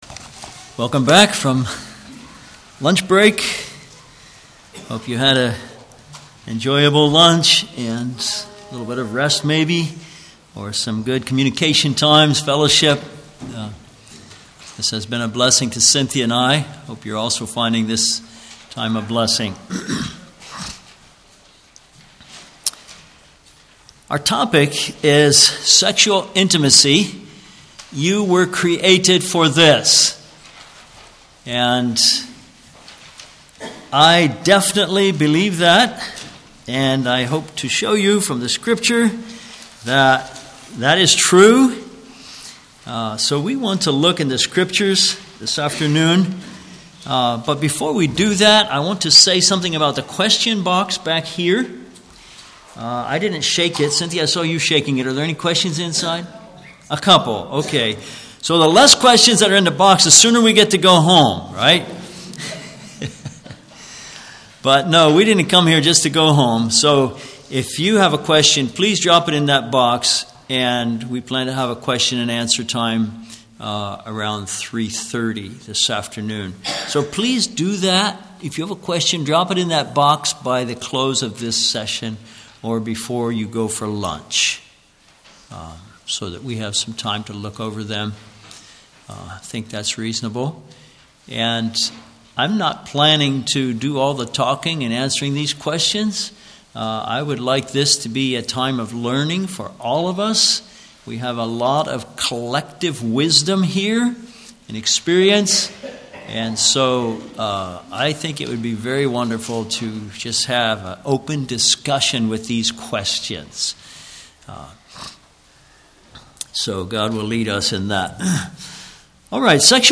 Service Type: Ladies Seminar, Men's Seminar